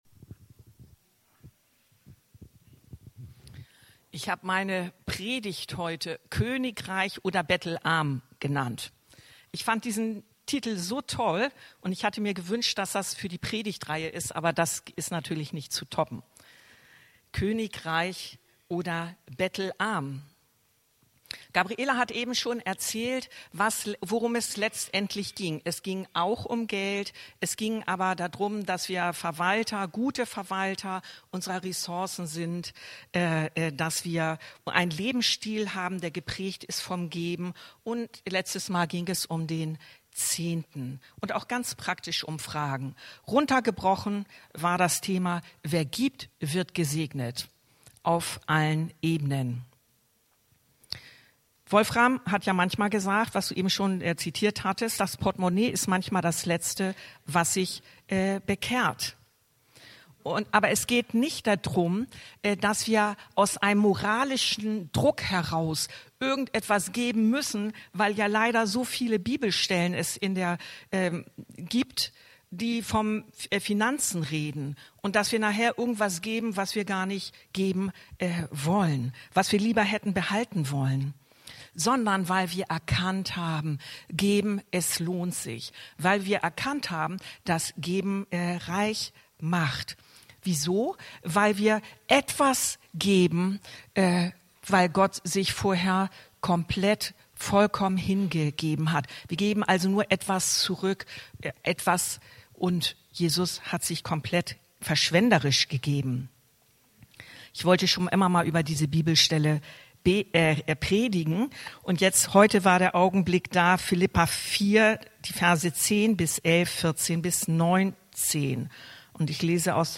Predigtreihe "KönigReich" 5: Königreich oder bettelarm?